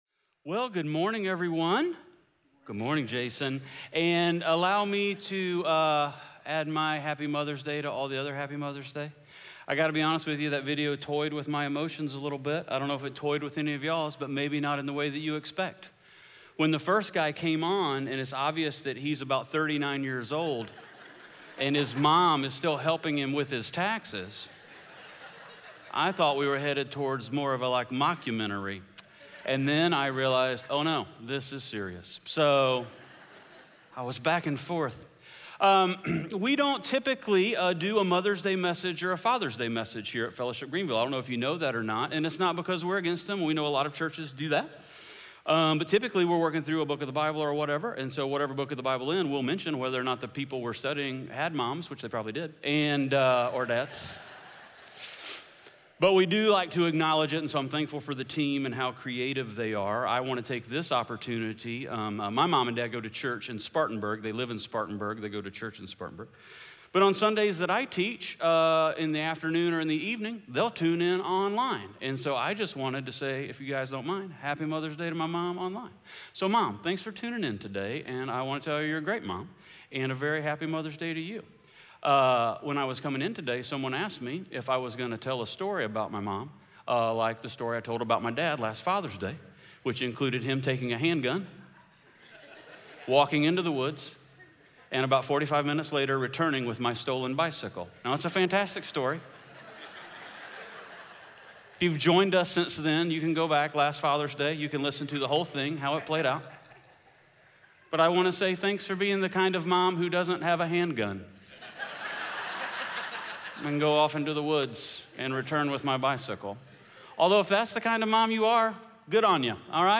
Acts 17:16-34 Audio Sermon Notes (PDF) Ask a Question Scripture: Acts 17:16-34 SERMON SUMMARY In Acts 17:16-34, we witness Paul's journey in Athens, a city steeped in idolatry and philosophy.